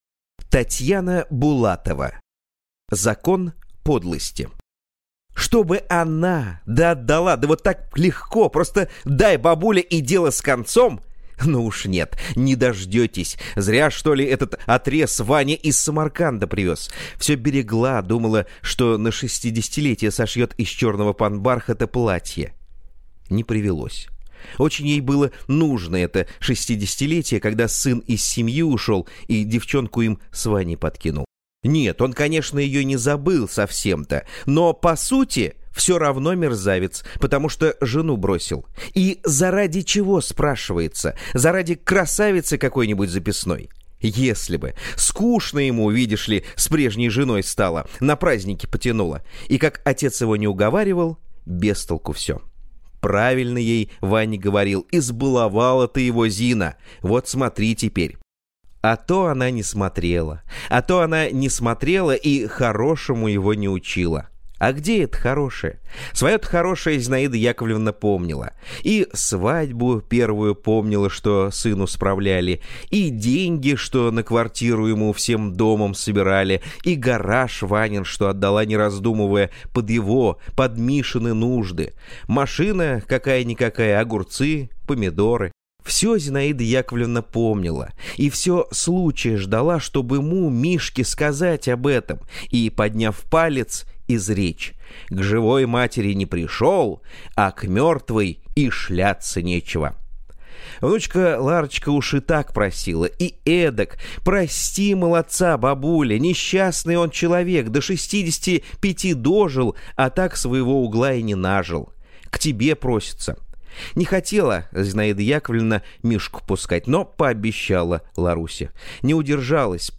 Аудиокнига Закон подлости | Библиотека аудиокниг